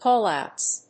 call+outs.mp3